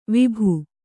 ♪ vibhu